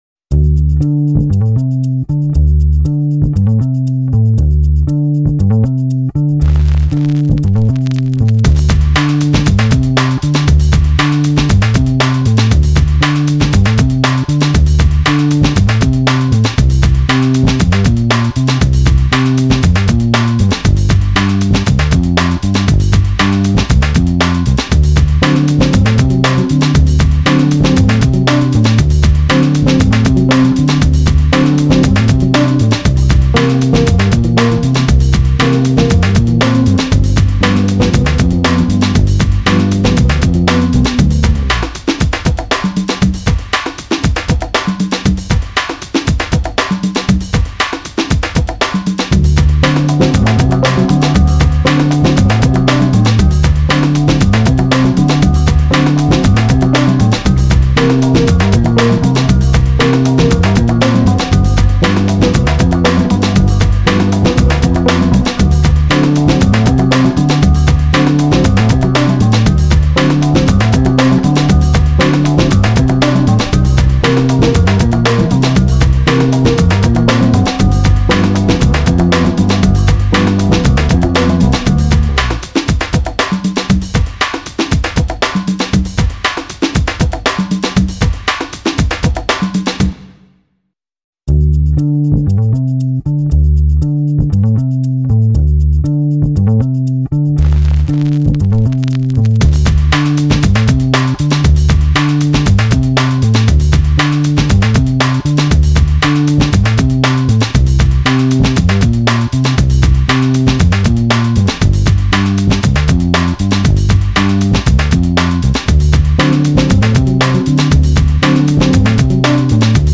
[R&B & Soul]